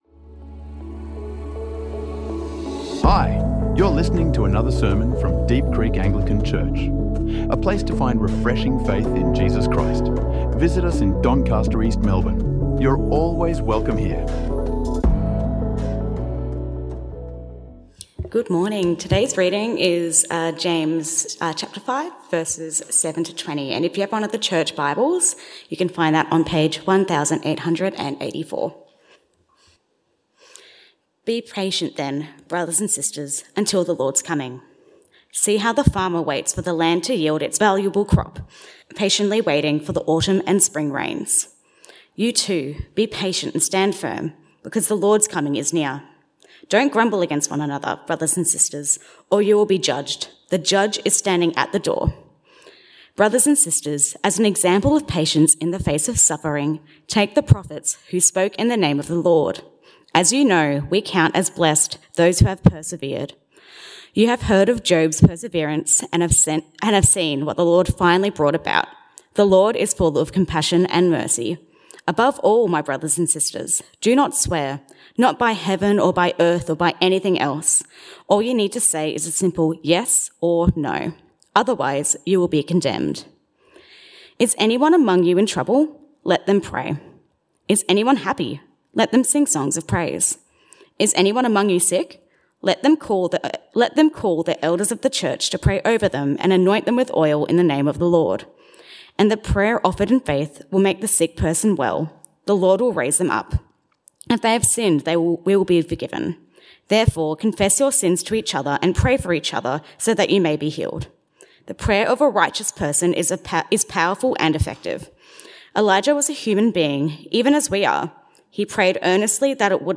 Discover the power of patience, integrity, and prayer in this sermon from James.